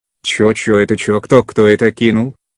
• Качество: 320, Stereo
забавные
Прикольный звук на смс-ку из смешного видео с совой)